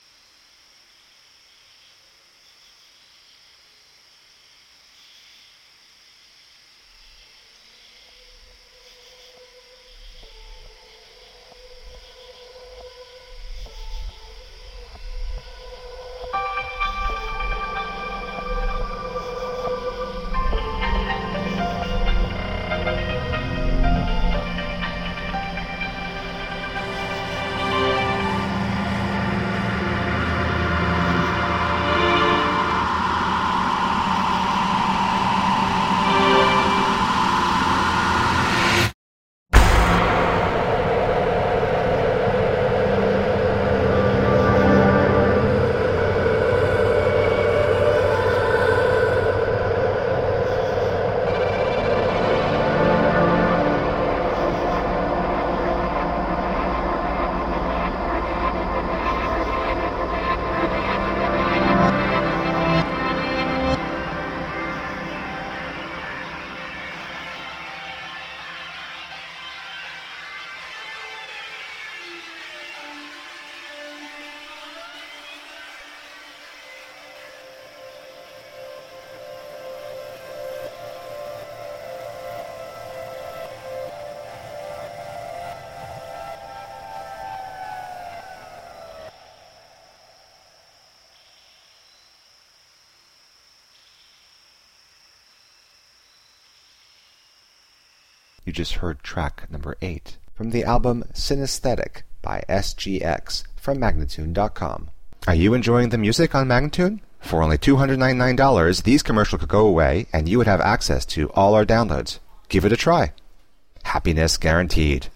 Straddling several electronica genres
Tagged as: Electro Rock, Ambient